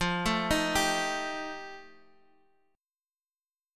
F9 chord